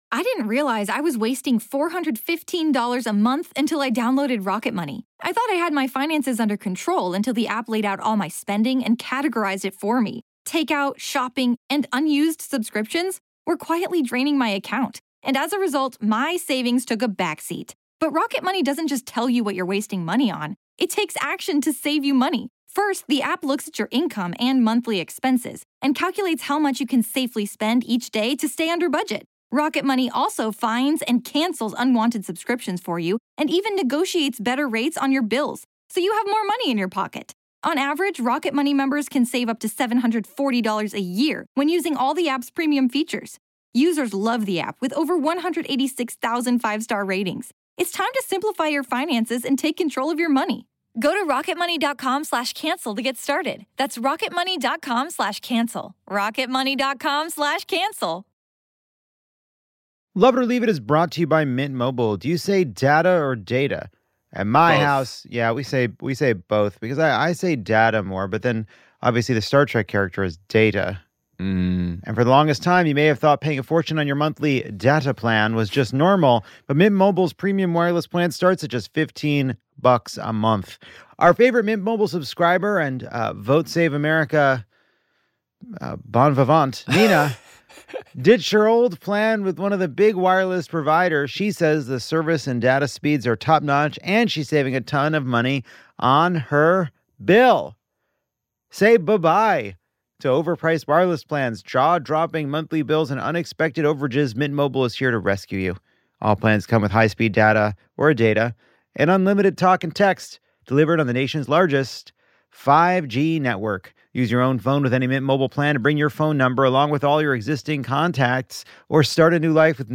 Lovett or Leave It returns to the beautiful Lincoln Theatre for a perfect night in our nation’s perfect capital.
Congressman Ro Khanna joins to talk about defending democracy and finding courage. Jen Psaki and Eugene Daniels talk softballs and hard truths. Federal workers are off the leash and biting back at DOGE, and Lovett and his guests offer their thoughts and prayers to phrases that are no longer serving us.